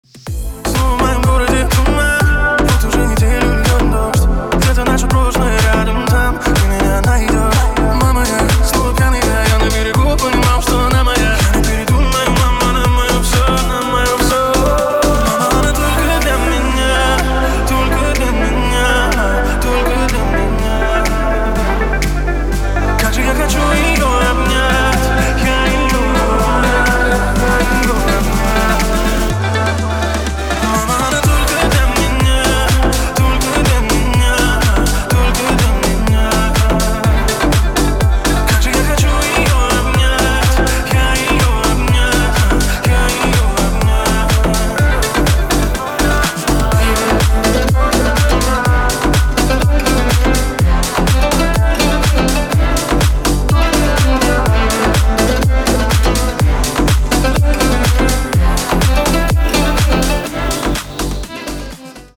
• Качество: 320, Stereo
мужской вокал
восточные мотивы
Club House
электронная музыка